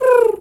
Animal_Impersonations
pigeon_2_call_03.wav